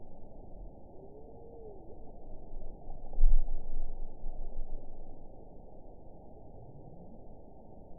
event 922426 date 12/31/24 time 22:52:01 GMT (11 months ago) score 9.14 location TSS-AB06 detected by nrw target species NRW annotations +NRW Spectrogram: Frequency (kHz) vs. Time (s) audio not available .wav